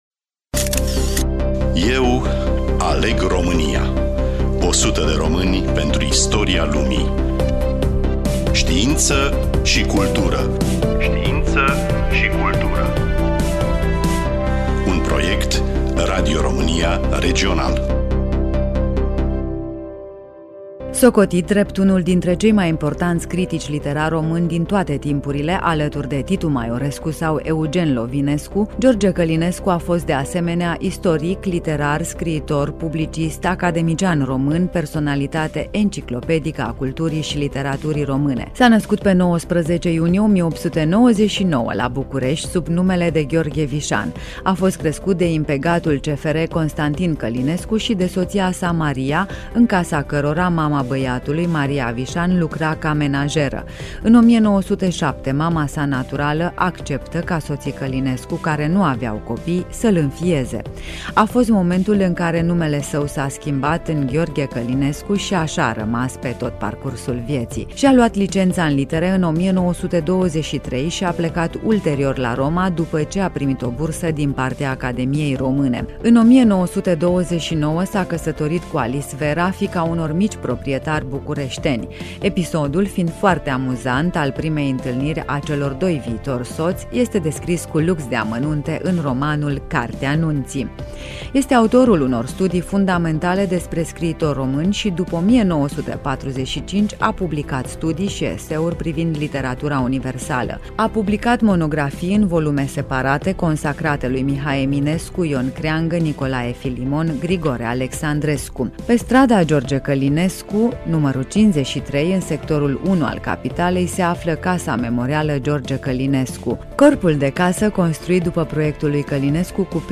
În fonoteca de aur de la Radio România a rămas mărturie înregistrarea discursului pe care George Călinescu l-a susţinut la Academia Română unde a fost sărbătorit la împlinirea a 60 de ani.
Studioul: Radio România Reşiţa